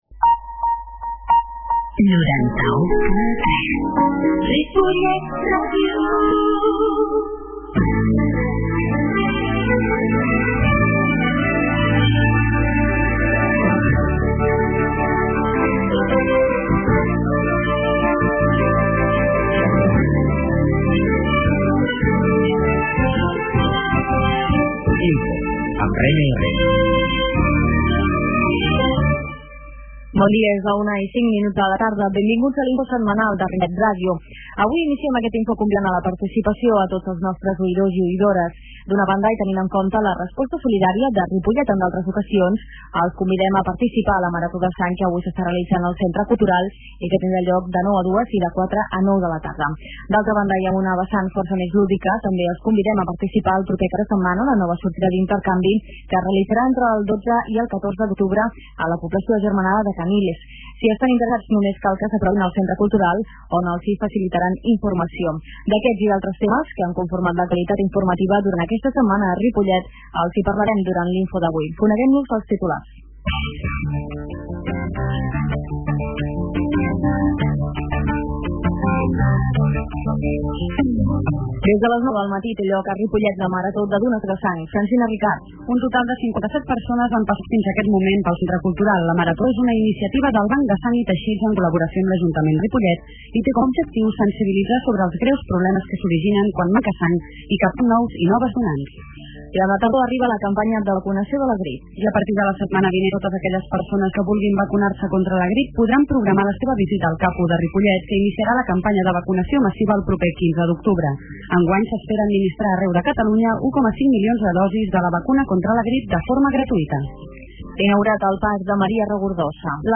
Entrevista amb Isabel Mesas, alcaldessa de Caniles, poblaci� agermanada amb Ripollet.